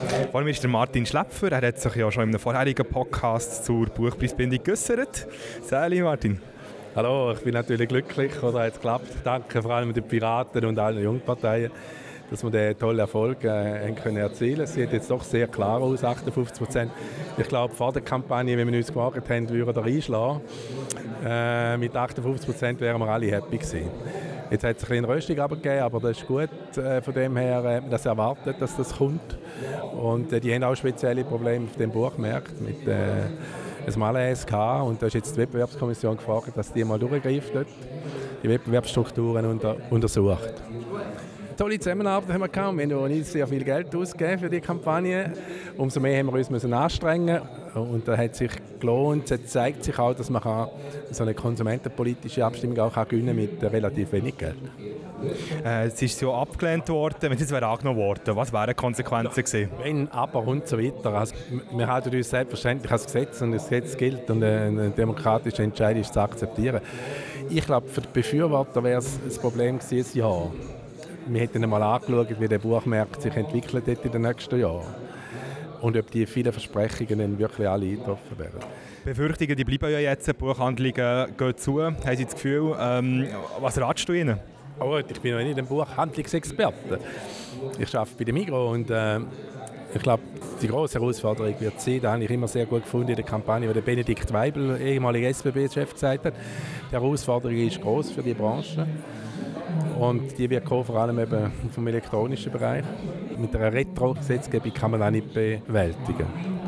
Abstimmungsparty